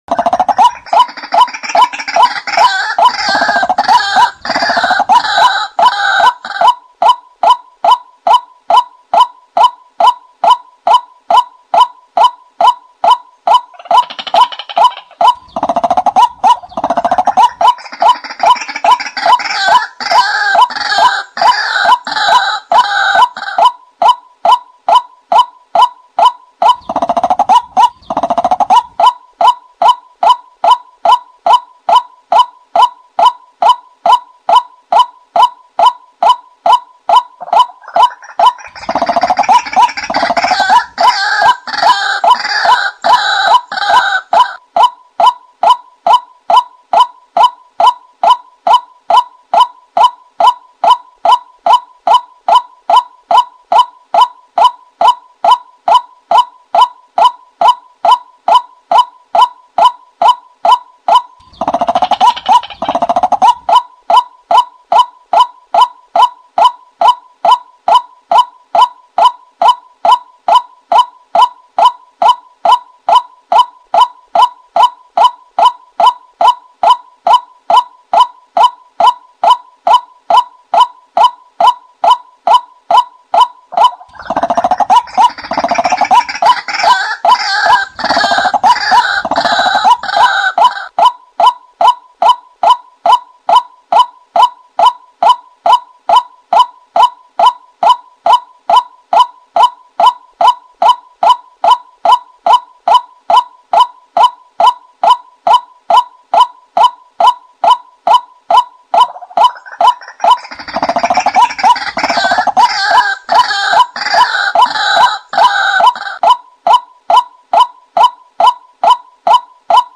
เสียงนกกวักต่อกลางวัน
หมวดหมู่: เสียงนก